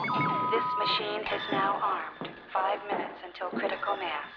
When Diana arms the nuclear device, a pinging sound like that from many submarine movies or TV shows like Voyage to the Bottom of the Sea is heard in the command center.
ping.wav